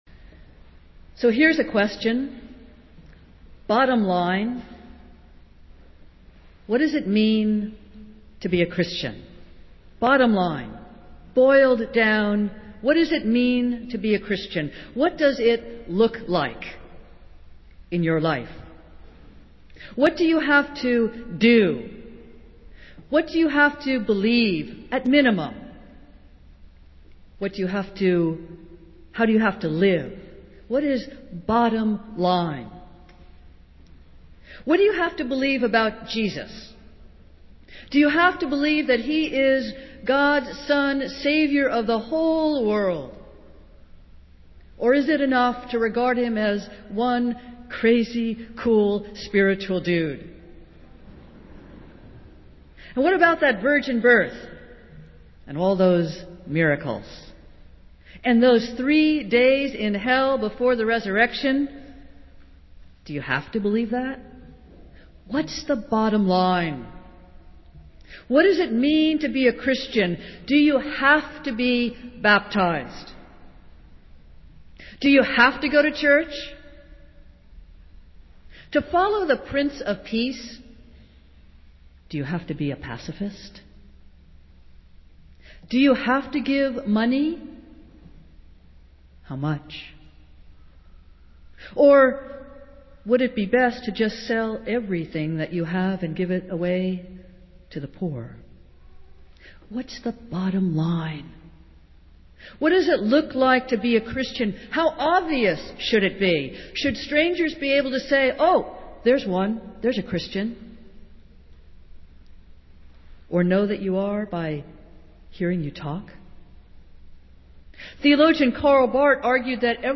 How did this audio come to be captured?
Festival Worship - Fifth Sunday in Lent